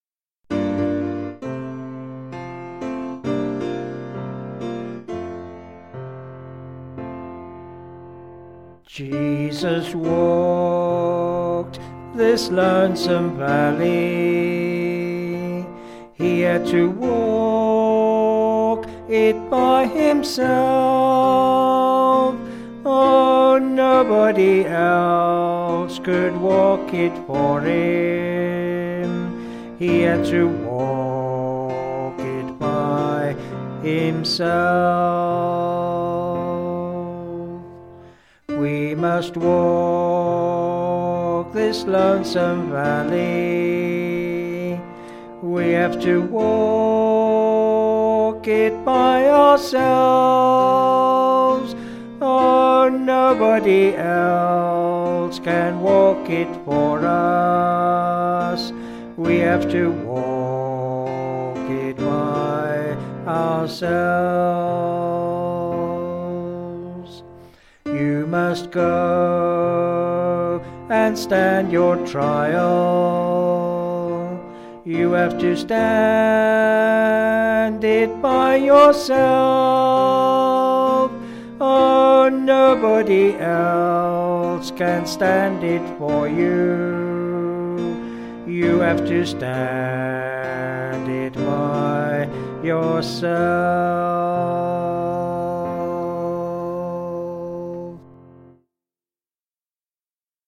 Vocals and Piano
Sung Lyrics